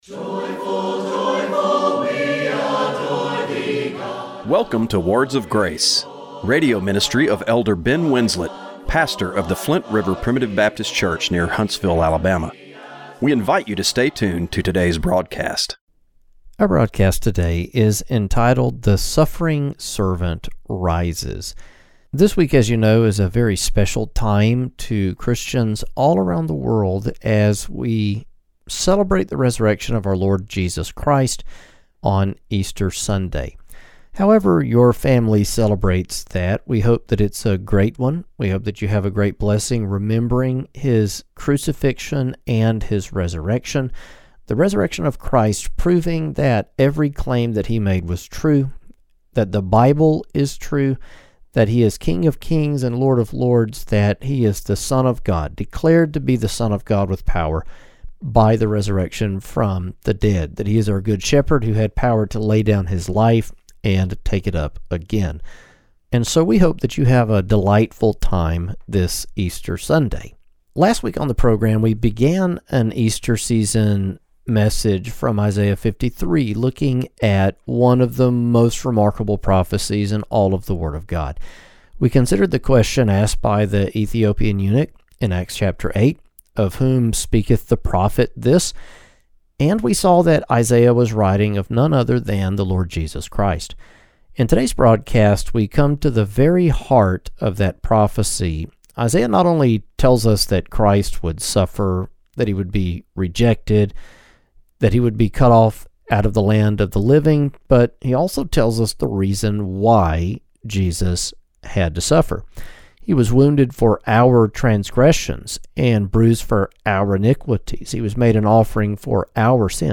Radio broadcast for April 5, 2026.